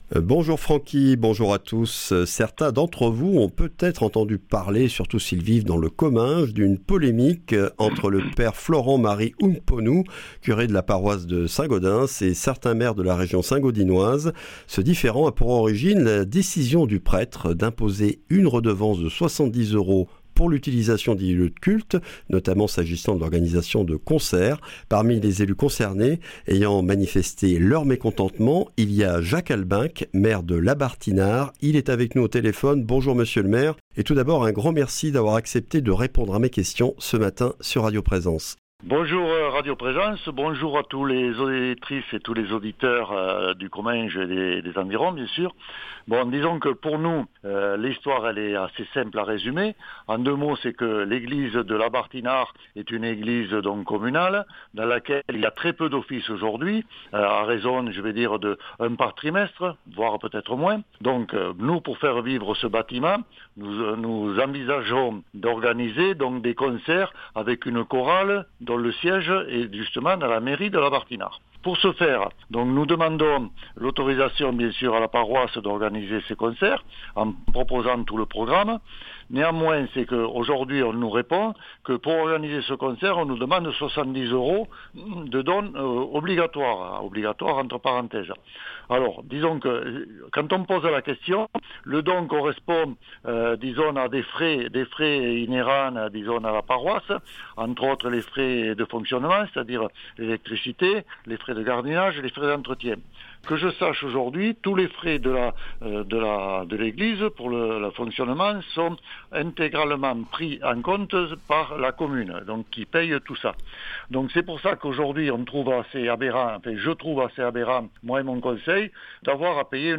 Au tour de Jacques Albenque, maire de Labarthe-Inard, d’expliquer pour quoi il refuse de payer cette redevance, tout en restant ouvert au dialogue.
Accueil \ Emissions \ Information \ Régionale \ Le grand entretien \ Redevance pour la location des lieux de culte en Comminges : réaction du (…)